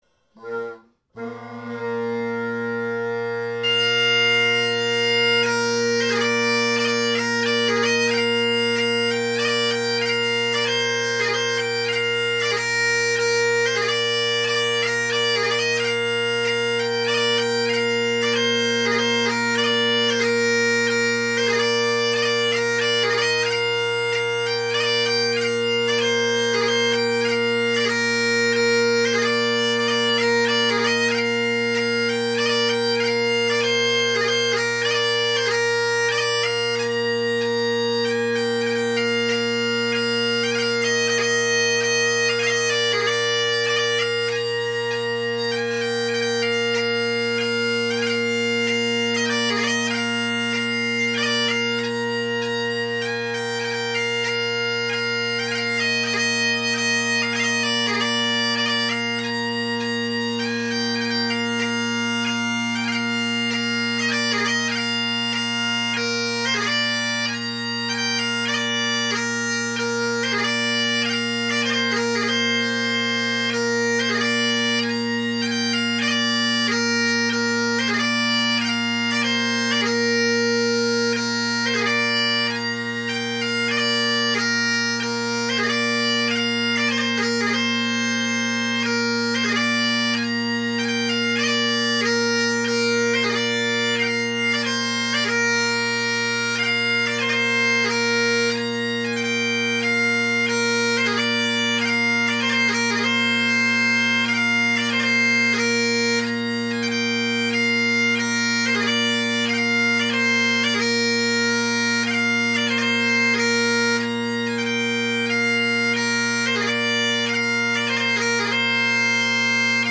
Modern Bagpipe Reviews
They can be heard in Bb here: